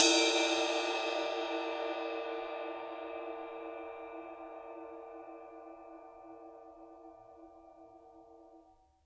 susCymb1-hitstick_f_rr2.wav